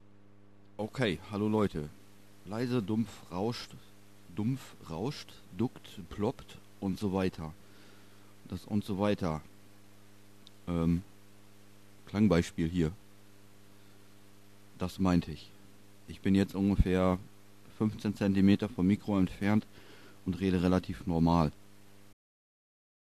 hallo hab mal ne frage zu folgendem versuchsaufbau. mikrofon: vivanco dm46>preamp: dap sc-14>soundchip: realtek 3d onboard>daw: reaper verkabelt komplett...
ok ja, kohlekapsel war vielleicht etwas übertrieben.. ich habe mal nen beispiel angehängt. da sieht und hört man, was ich meine... was mir auch komisch vorkommt ist, dass die clipping lampe am preamp auf dauerfeuer an ist, sobald am pc angestöpselt..